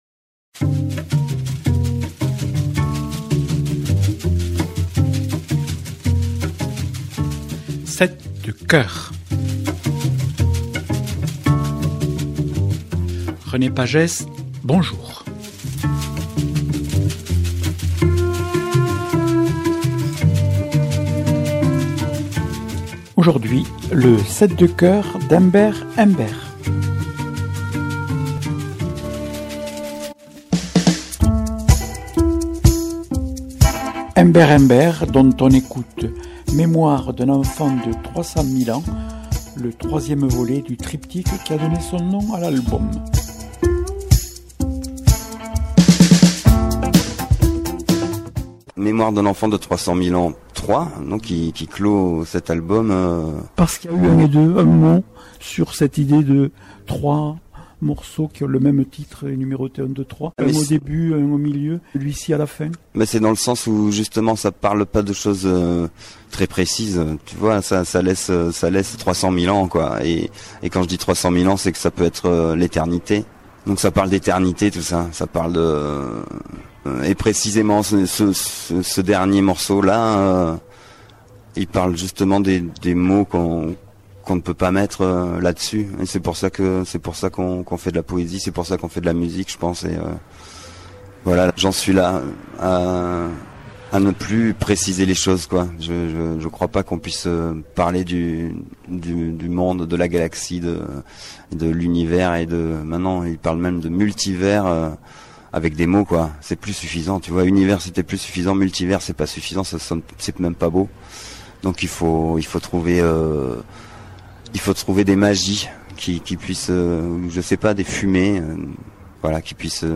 Émissions
contrebassiste et chanteur.